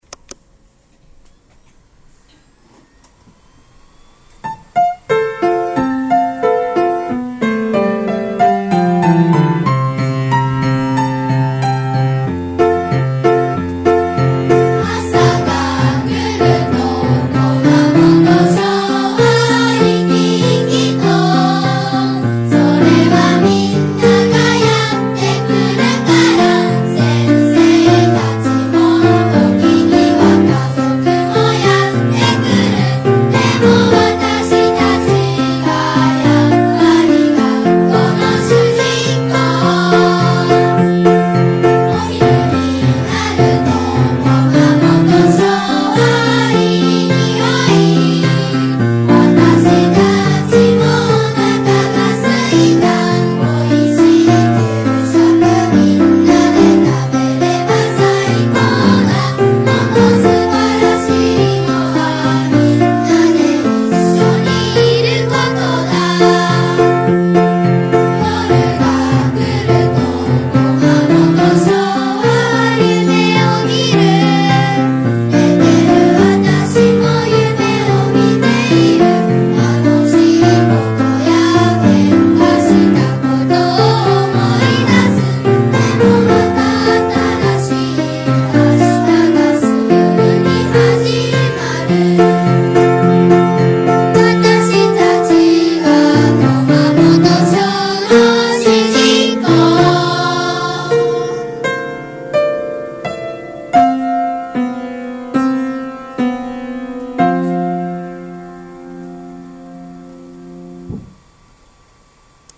演奏：文京区民オーケストラ
合唱：駒本小児童